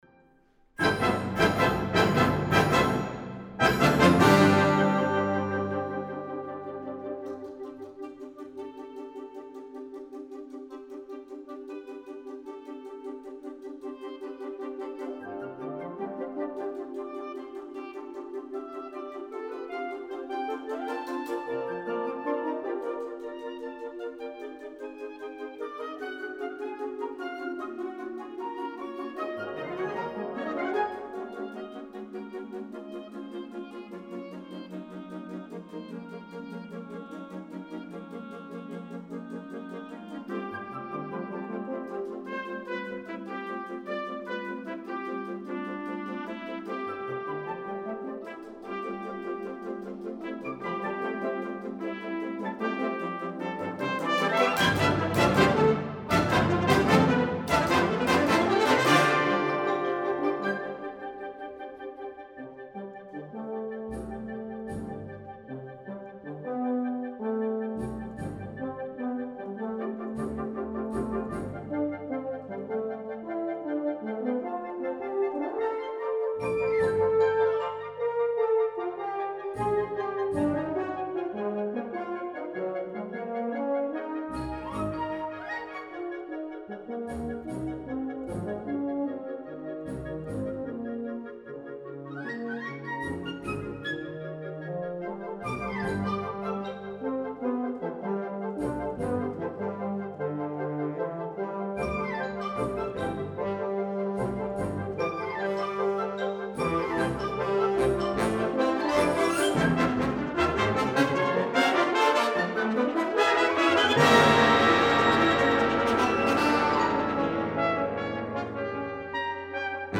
major works for winds